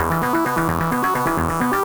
Index of /musicradar/8-bit-bonanza-samples/FM Arp Loops
CS_FMArp A_130-E.wav